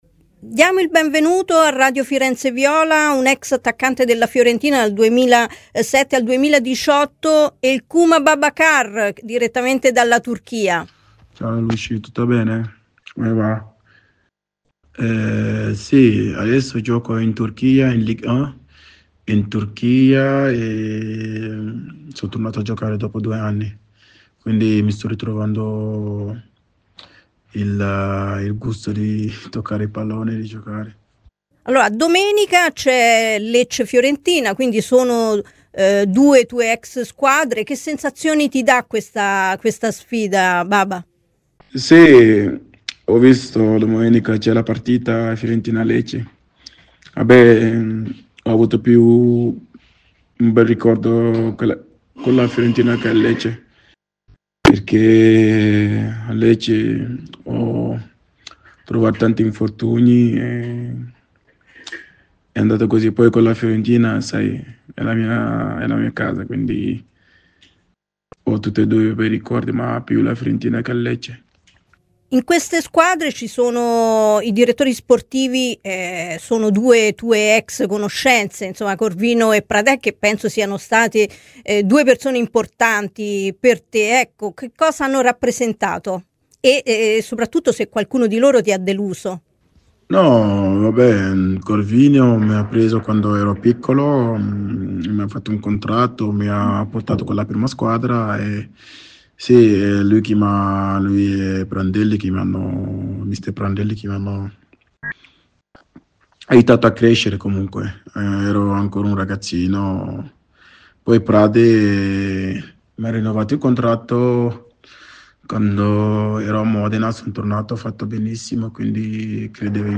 L'ex attaccante viola Khouma El Babacar è intervenuto a Radio FirenzeViola durante "Viola Amore mio" per parlare di Lecce-Fiorentina, direttamente dalla Turchia dove dall'estate è tornato a giocare al Boluspor, dal Copenaghen: "Si sono tonato a giocare in Turchia dopo due anni e sto ritrovando il gusto di toccare il pallone e giocare" inizia.